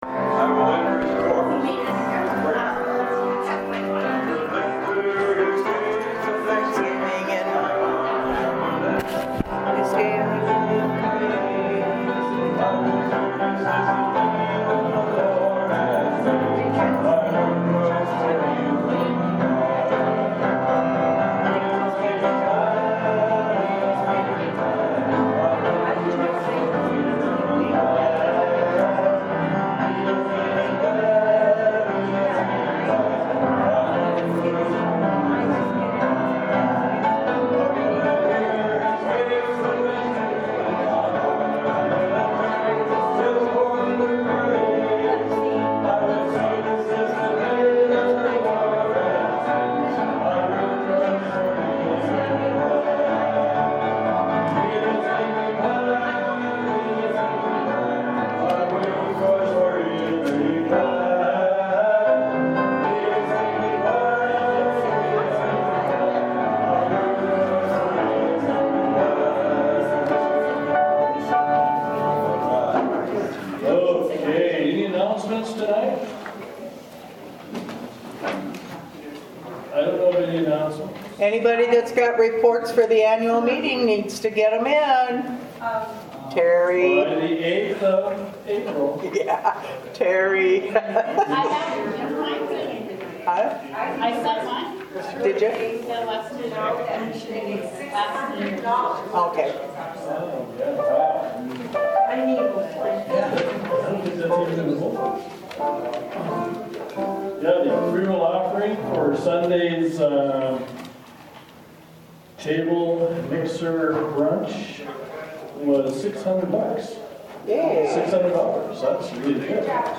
Wed lent service